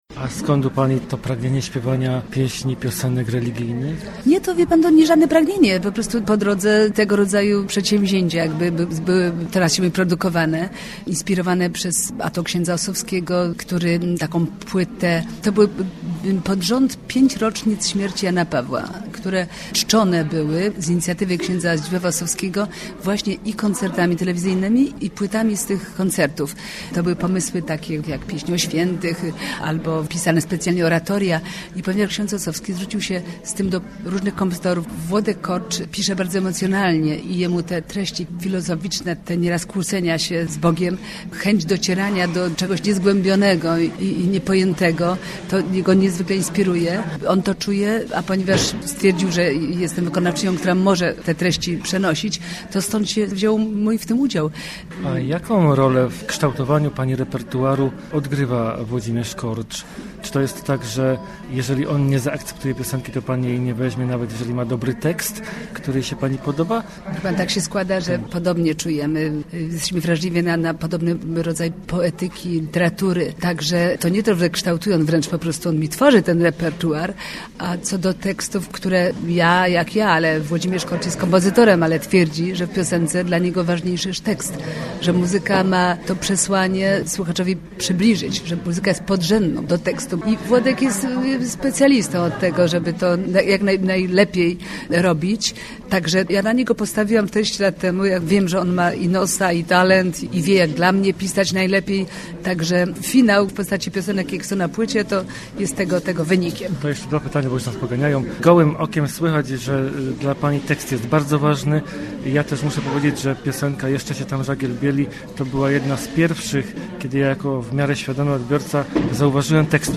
po jej koncercie w Kórniku.